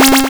bfxr_Select.wav